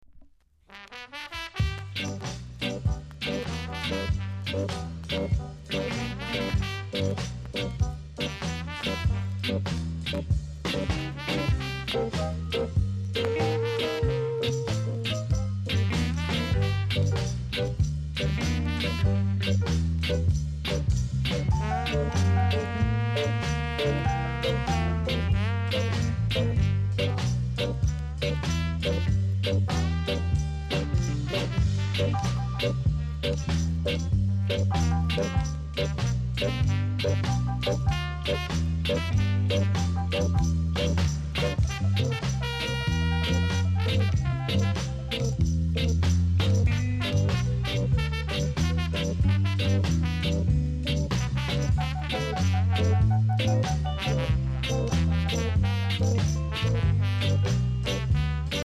※チリ、パチノイズが少しあります。